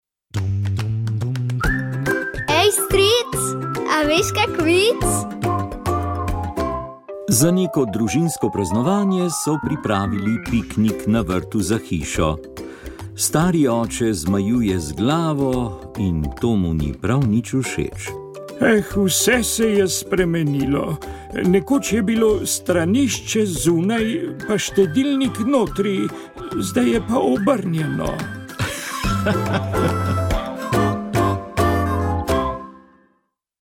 V »Pogovoru o« smo se tokrat spraševali »Kje je naša elita?« V razpravi o elitah v slovenski družbi, ki smo jo pripravili skupaj s civilno iniciativo Prebudimo Slovenijo, so svoje poglede predstavili trije ugledni gostje.